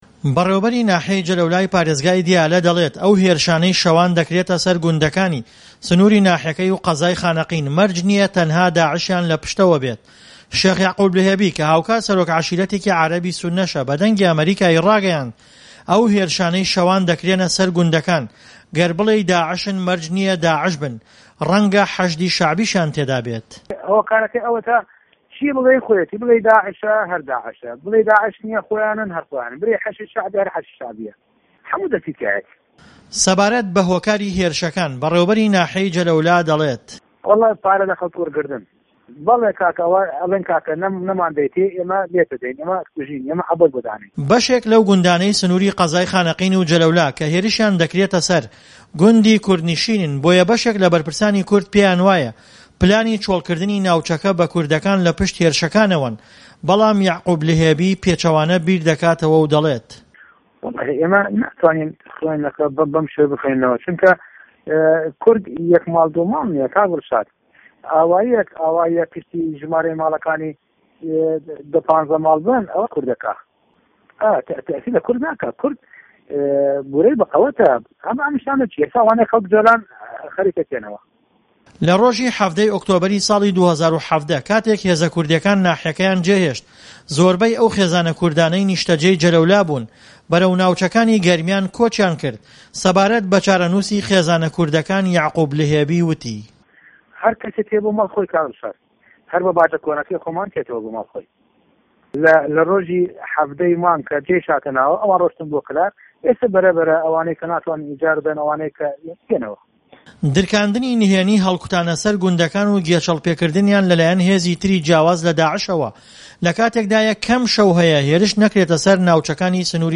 شێخ یەعقوب لهێبى کە هاوکات سەرۆک عەشیرەتێکى عەرەبى سونەشە، بە دەنگى ئەمەریکاى ڕاگەیاند"ئەو هێرشانەى شەوان دەکرێنە سەر گوندەکان گەر بڵێی داعش نین، داعشن، گەر بیشڵێى حەشدى شەعبى نین، حەشدیشن، هەموو لایەک دەستیان لەو هێرشانەدا هەیە."